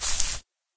default_grass_footstep.1.ogg